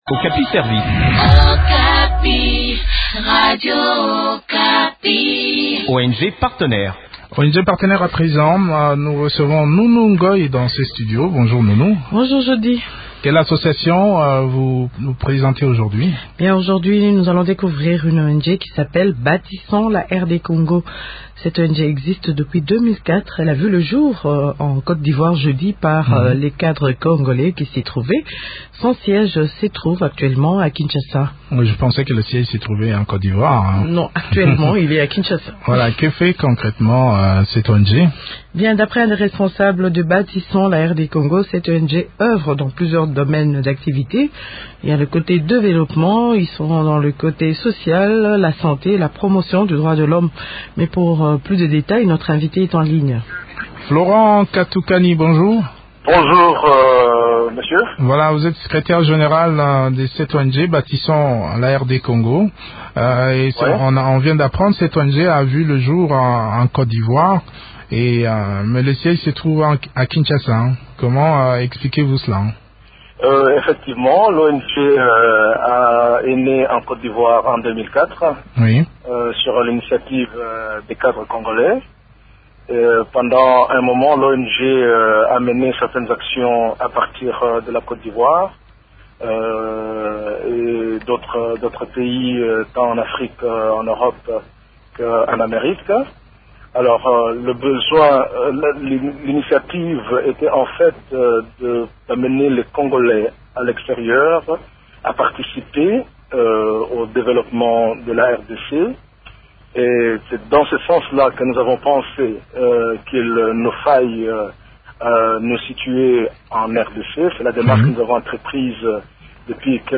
Créé depuis 2004, l’ONG Bâtissons la Rd Congo travaille pour le développement social, la santé et la promotion des droits de l’homme. Eclairage sur les activités de cette organisation dans cet entretien